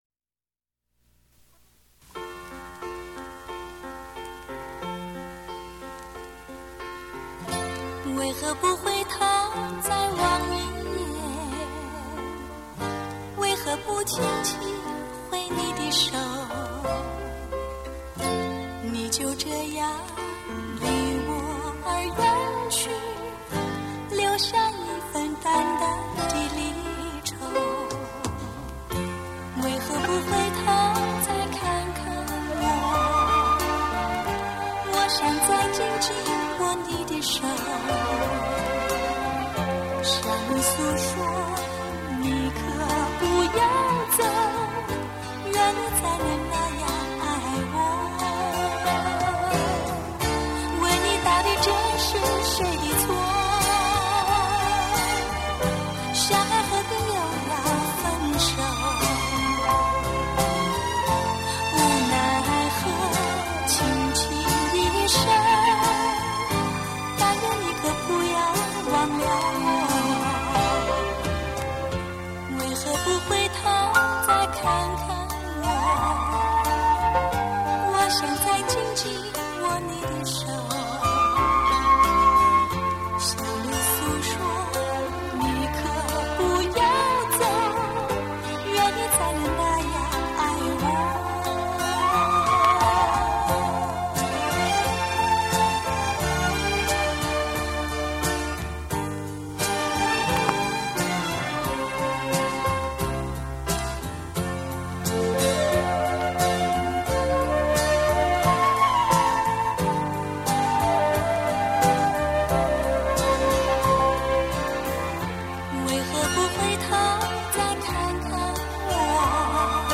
脍炙人口怀念金曲
温馨甜蜜耐人寻味